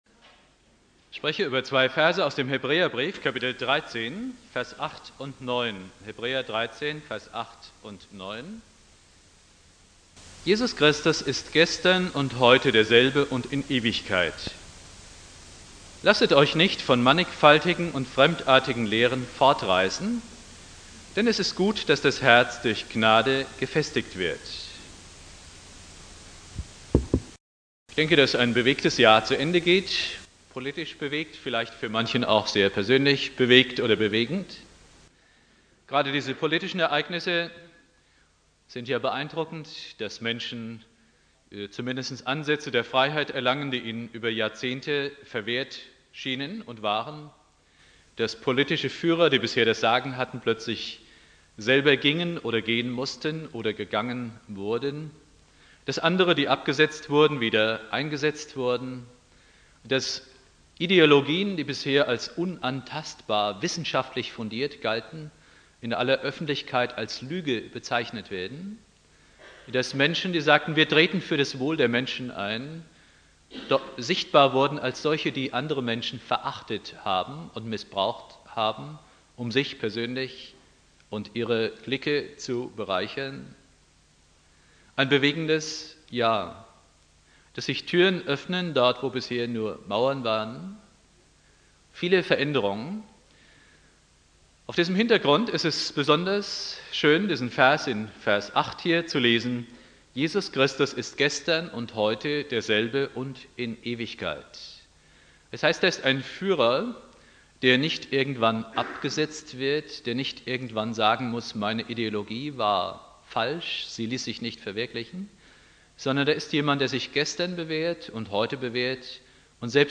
Predigt
Silvester Prediger